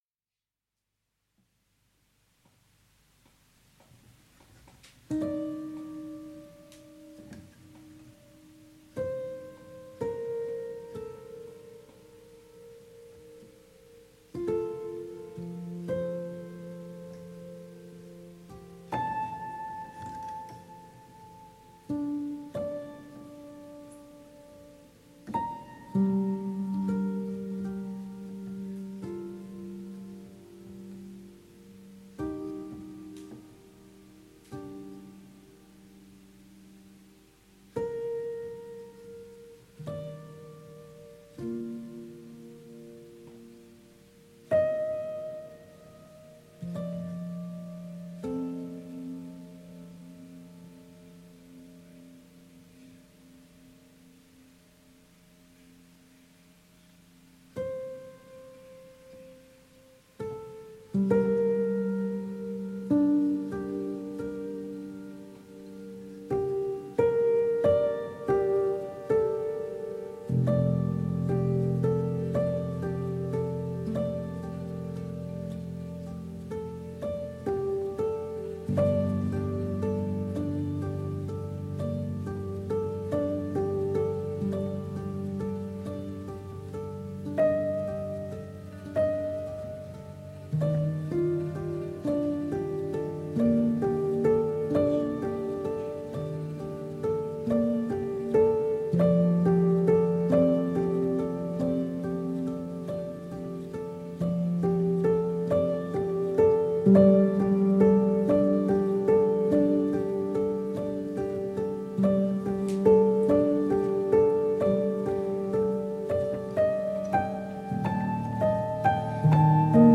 پیانو سولو
Piano solo